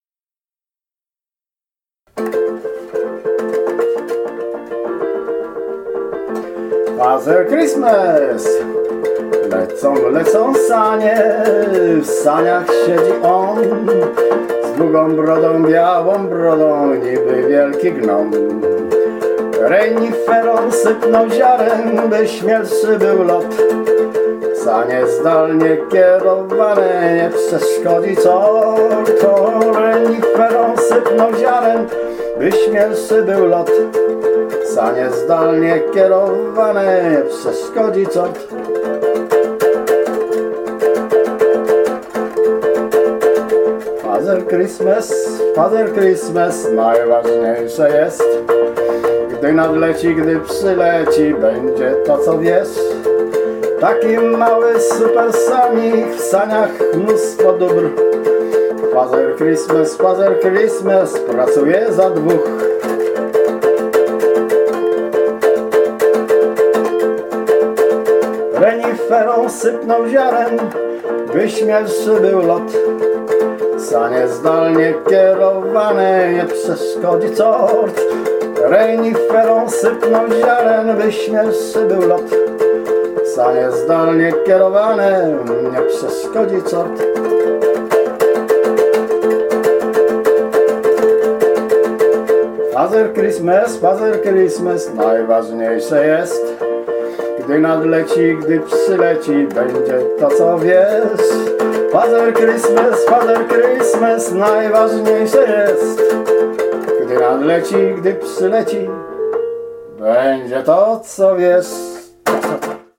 kolędy na wesoło